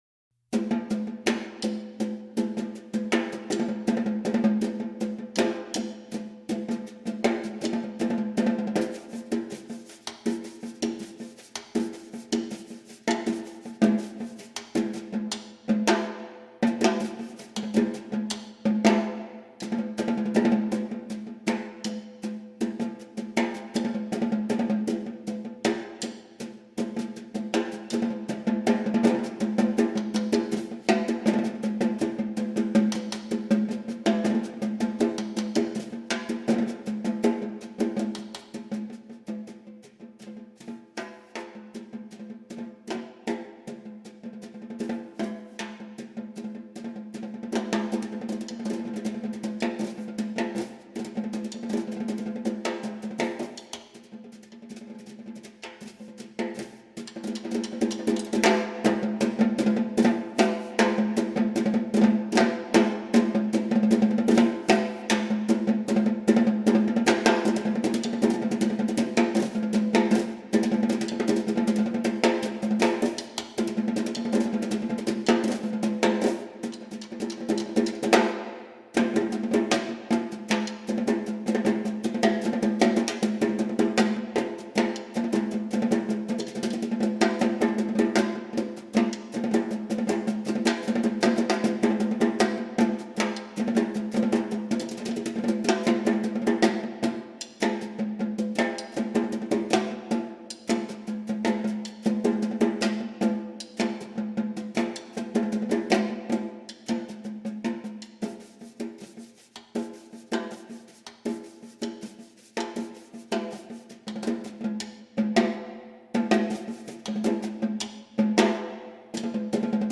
Voicing: Snare Drum Duet